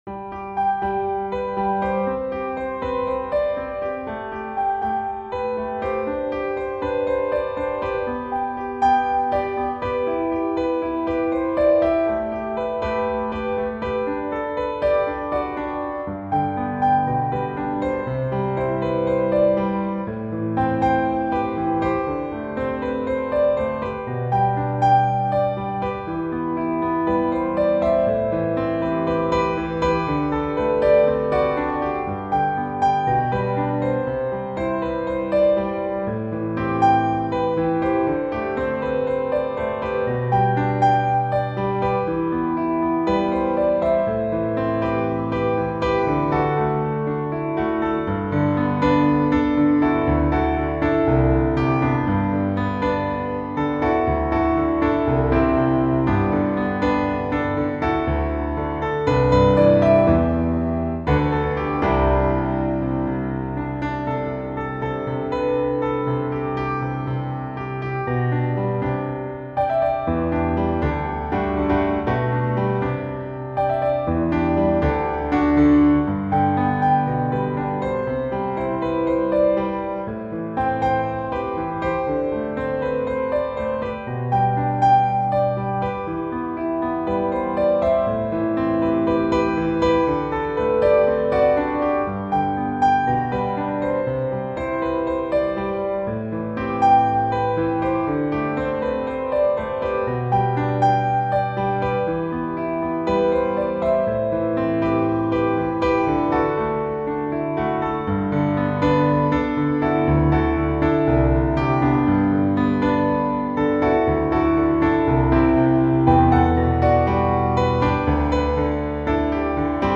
Early Intermediate Solo
reflective pop hit
piano solo